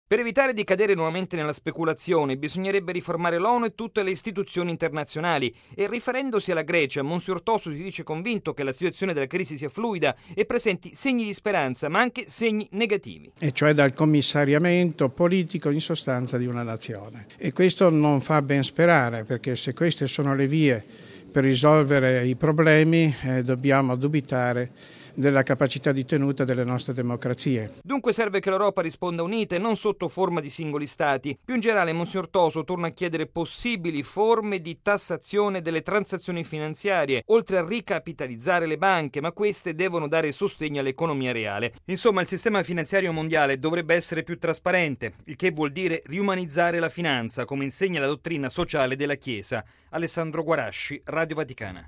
Lo ha affermato mons. Mario Toso, segretario del Pontificio Consiglio Giustizia e Pace, intervenuto a Roma a un convegno sul contributo della Dottrina Sociale della Chiesa per uscire dalla crisi. Dunque, per mons. Toso, bisogna arrivare a una riforma dell’Onu.